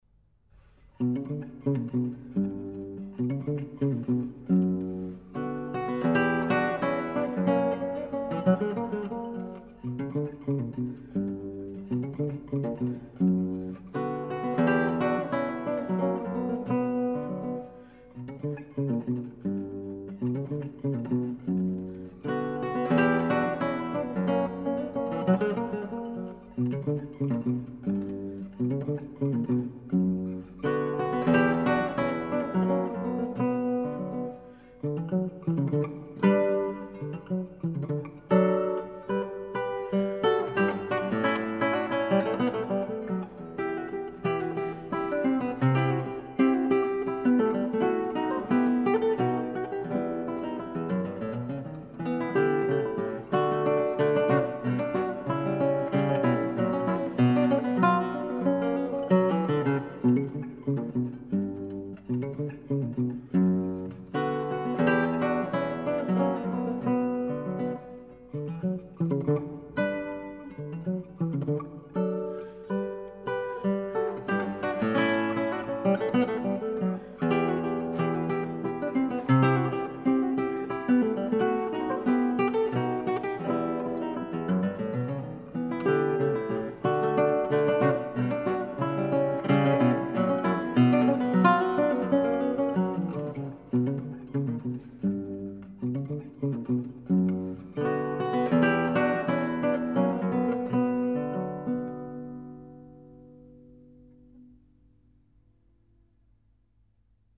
Alexandre Lagoya, Guitar